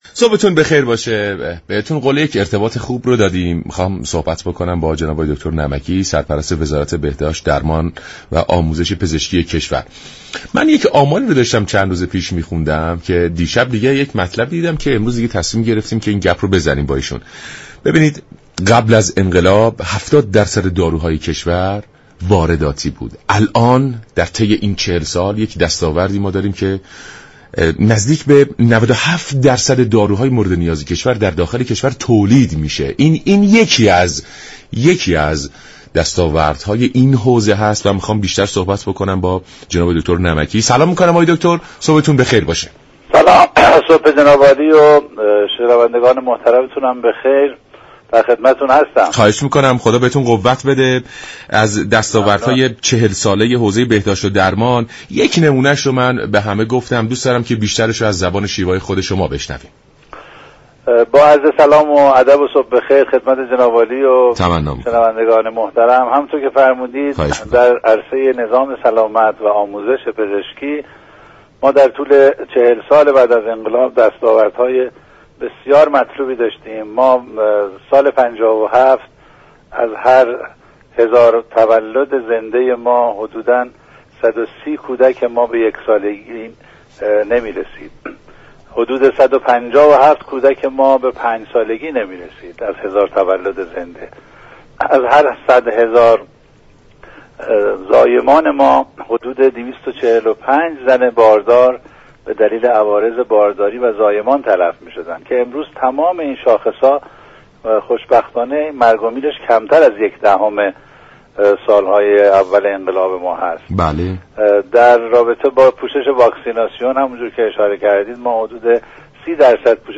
سرپرست وزارت بهداشت، درمان و آموزش پزشكی در گفت و گو با رادیو ایران گفت: امروز 70 درصد افراد، تحت پوشش طرح واكسیناسیون هستند.